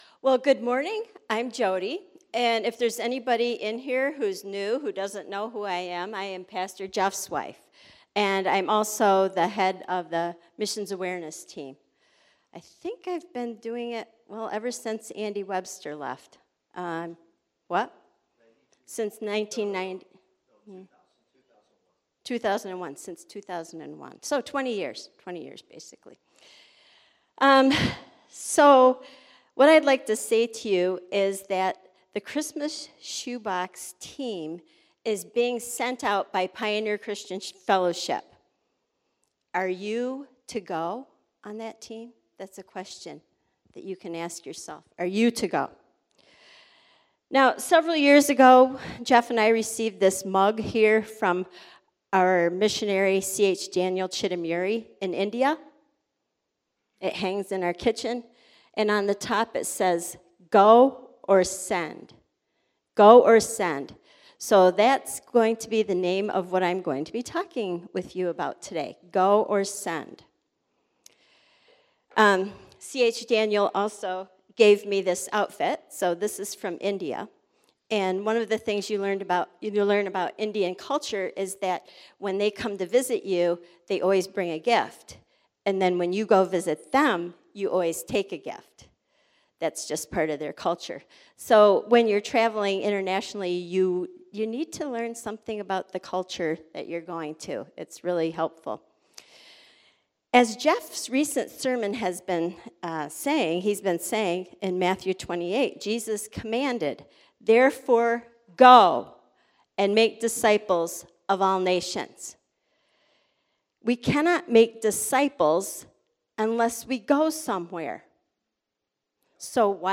This week we continue our series while also highlighting Missions Sunday.